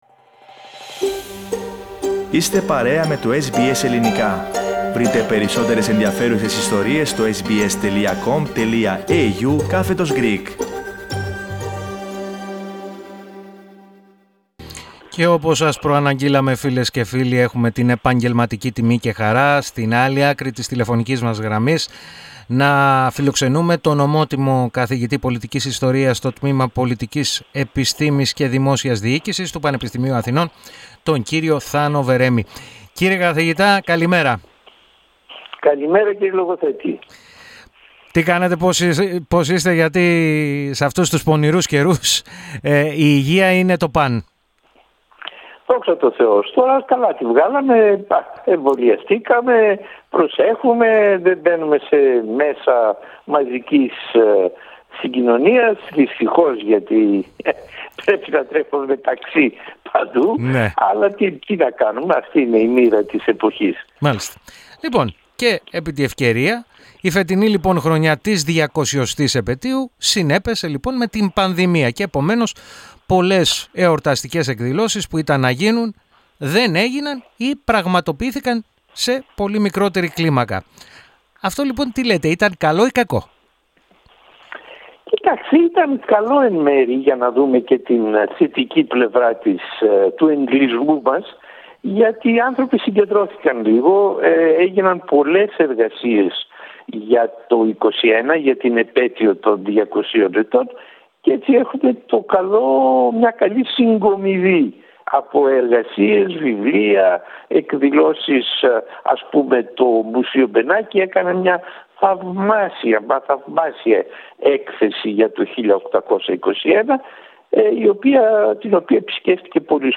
Ο ομότιμος καθηγητής Πολιτικής Ιστορίας στο τμήμα Πολιτικής Επιστήμης και Δημόσιας Διοίκησης του Πανεπιστημίου Αθηνών, Θάνος Βερέμης, μίλησε στο Ελληνικό Πρόγραμμα της ραδιοφωνίας SBS, κάνοντας έναν απολογισμό του φετινού έτους της διακοσιοστής επετείου από την έναρξη της Ελληνικής Επανάστασης.